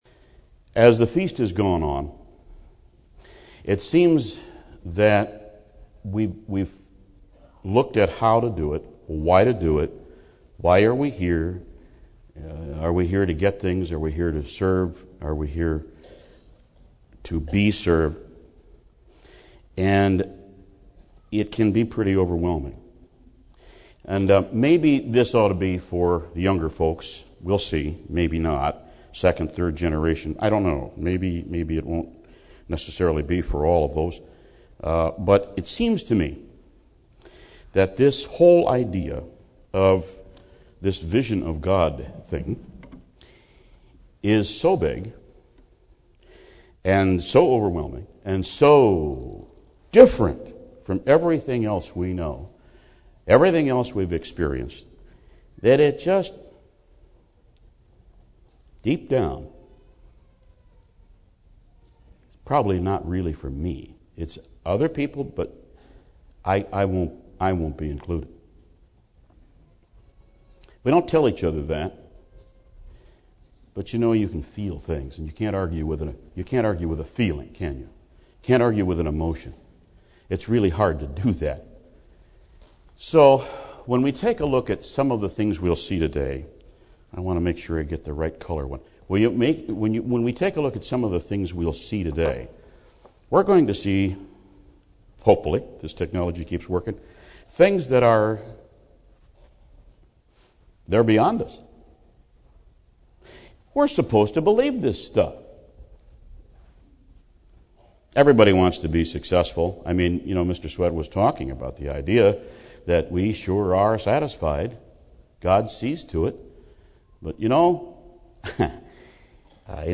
This sermon was given at the New Braunfels, Texas 2013 Feast site.